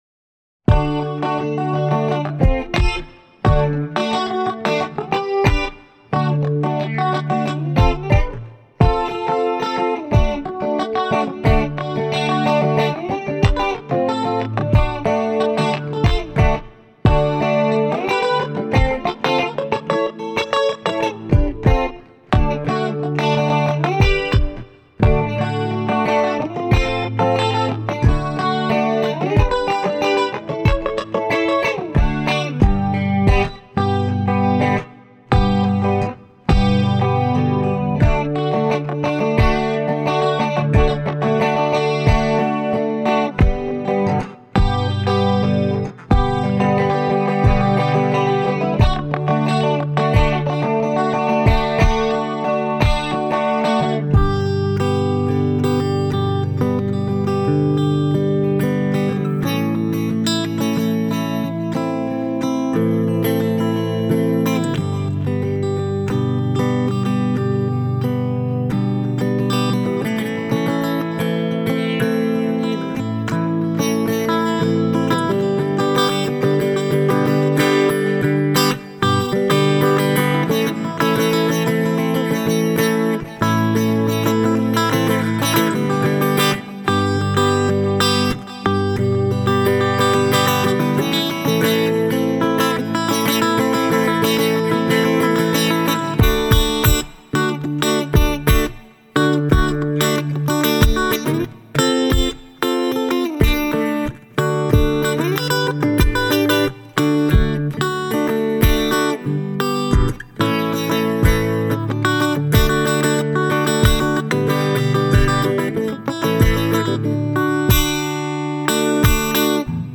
As a result, I have concluded that my rambling through this preset should be called "Halve A Guitar" (oh, perish the thought)!!